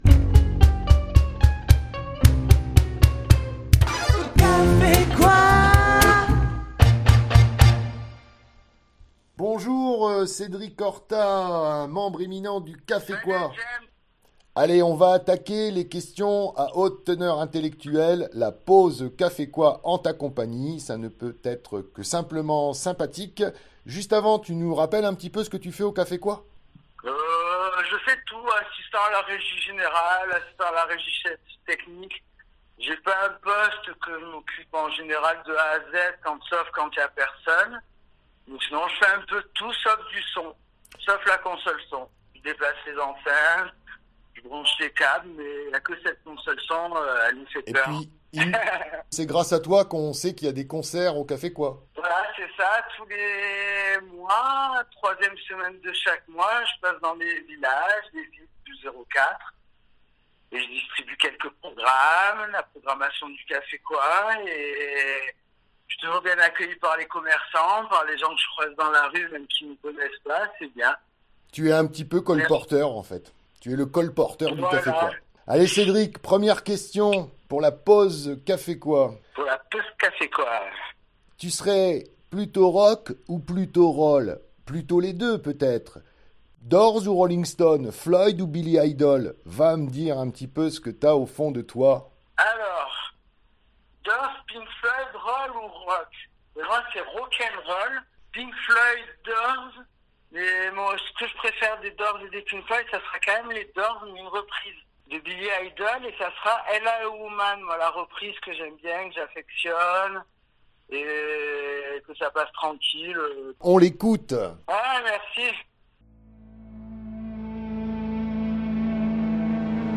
Fréquence Mistral et le K'fé Quoi Forcalquier se retrouvent et partagent un rendez-vous " détente " et musical durant lequel, à tour de rôle, un membre de l'équipe vous confie ses goûts musicaux que nous vous diffusons dans la foulée.